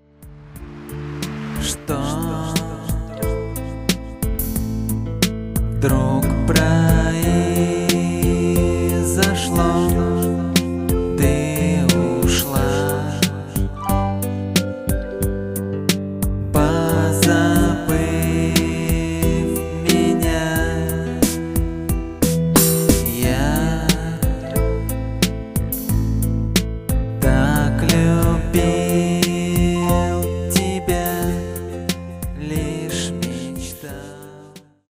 • Жанр: Легкая